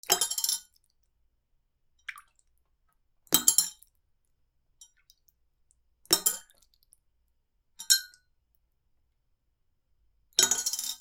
水の入ったコップにコインを入れる